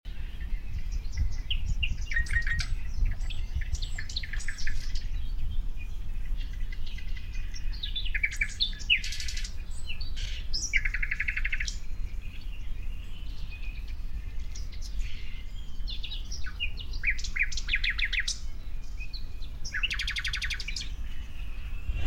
Nachtigall Gesang
Melodisch, laut, abwechslungsreich – mit klaren Tönen, Trillern, Pfiffen und Strophen.
Nachtigall-Gesang-Voegel-in-Europa.mp3